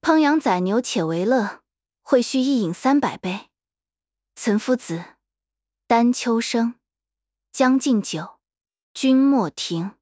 Text-to-Speech
Spark TTS finetuned in genshin charactors voices.